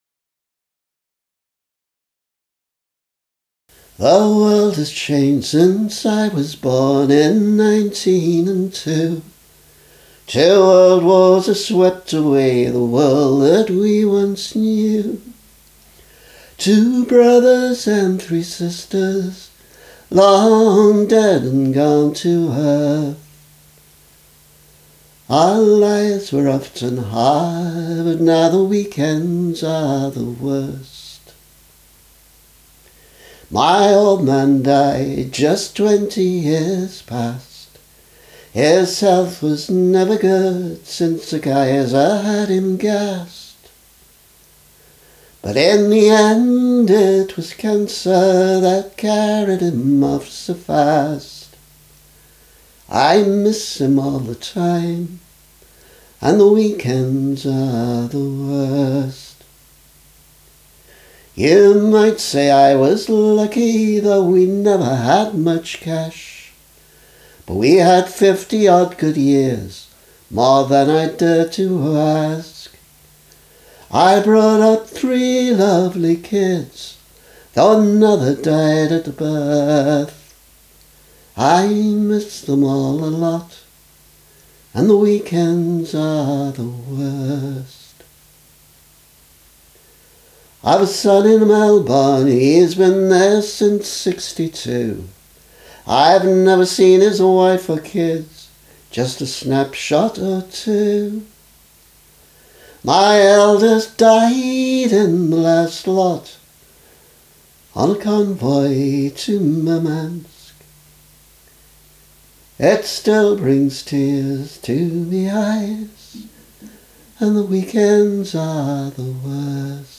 The tune is a heavily adapted mash-up of two traditional melodies.
emastered_weekends-vocal.mp3